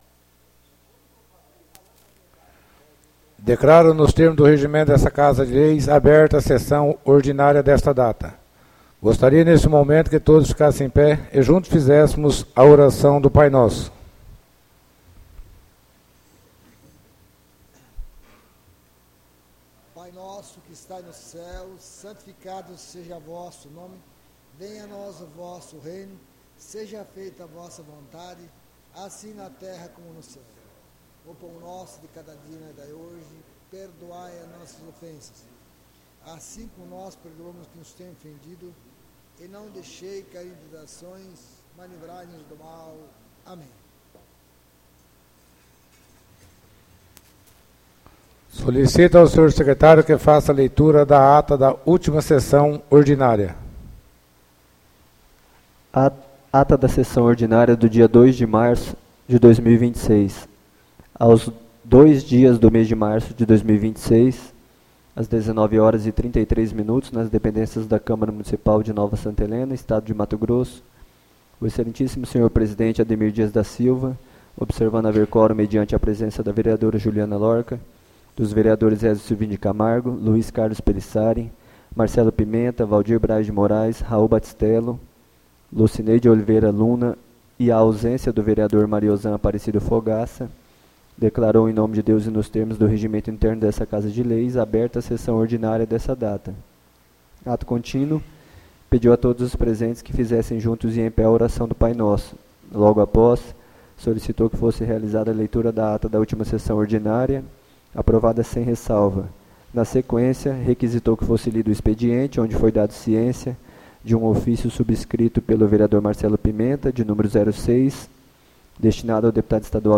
ÁUDIO SESSÃO ORDINÁRIA E SESSÃO SOLENE DA VOTAÇÃO DA MESA 09-03-26